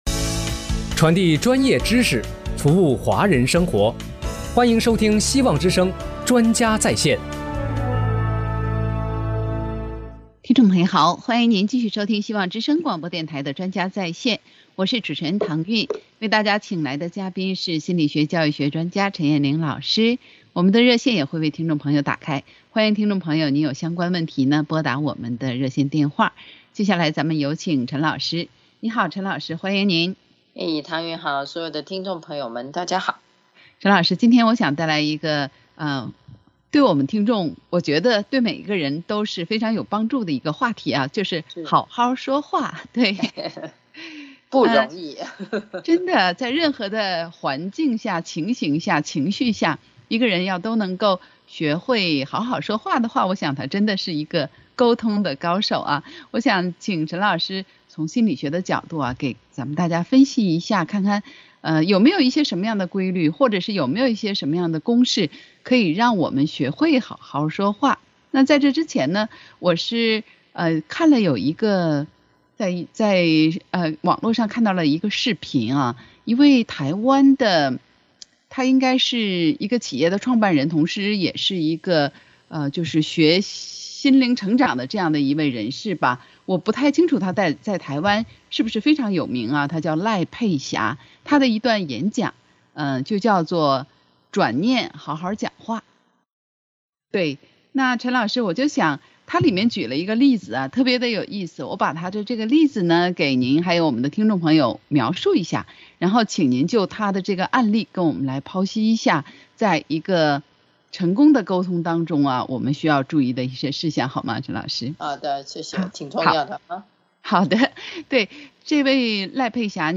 【希望之聲2023年1月18日】（主持人